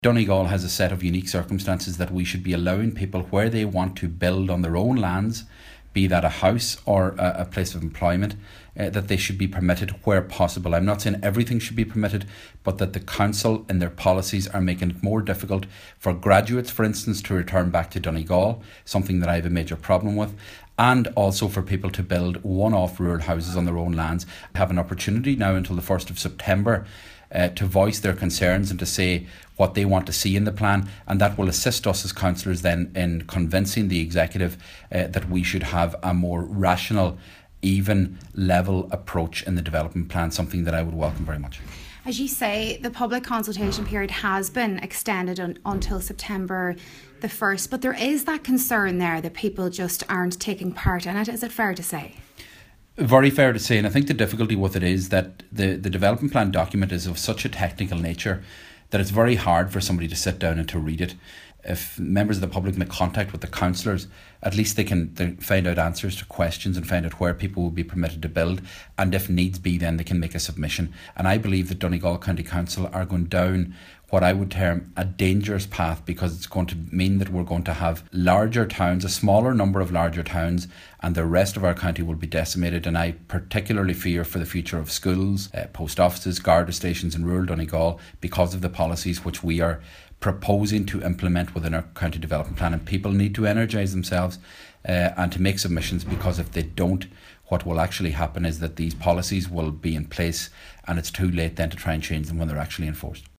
Cllr. Seamus O’Domhnaill says the plan is of huge importance to the county and once finalised it cannot be altered.
He’s warning that it could have serious implications if the plan is not studied or questioned……………